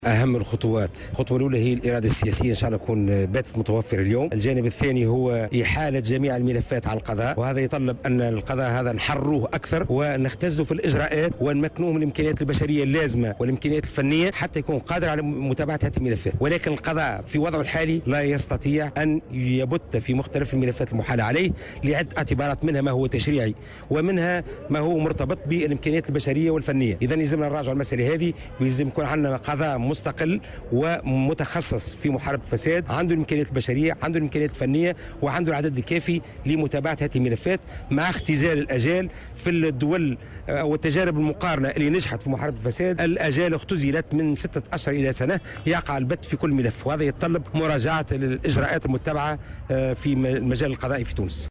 وأضاف في تصريح اليوم لمراسلة "الجوهرة أف أم" على هامش ندوة صحفية عقدها عدد من الخبراء والشخصيات الحقوقية والسياسية، لتسليط الضوء على تصورات المرحلة المقبلة وما يجب أن تتضمنه خارطة الطريق، أنه يجب أن يكون القضاء مستقلا ومتخصصا في محاربة الفساد وله كافة الإمكانيات البشرية والفنية، مع اختزال الآجال ومراجعة الإجراءات المُتبعة.